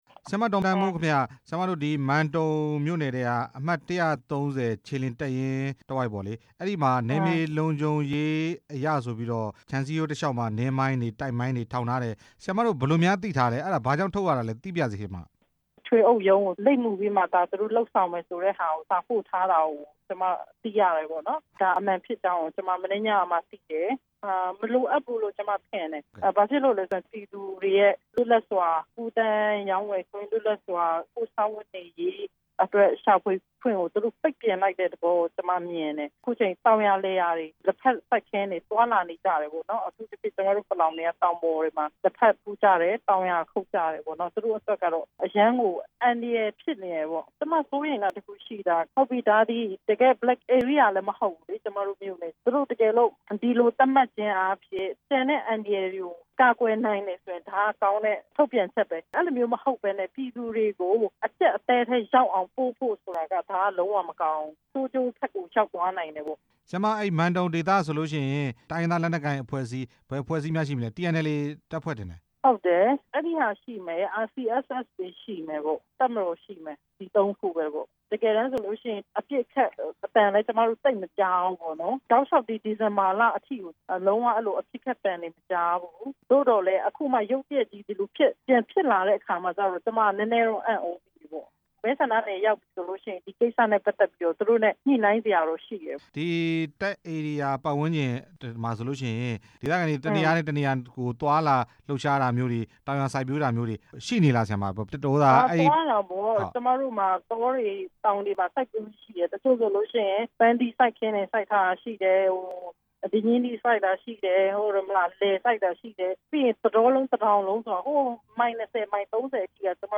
မိုင်းထောင်ထားမှု တပ်ရင်းတာဝန်ရှိသူတွေနဲ့ ညှိနှိုင်းမယ့်အကြောင်း မေးမြန်းချက်